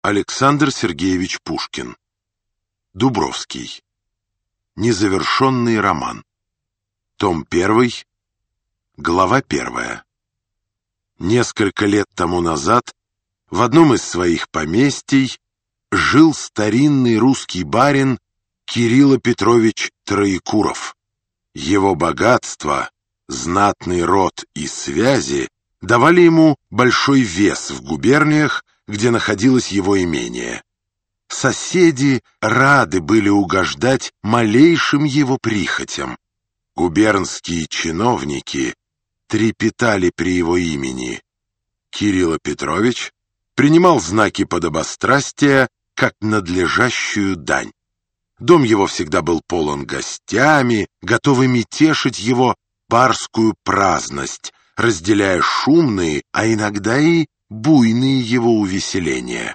Аудиокнига Дубровский | Библиотека аудиокниг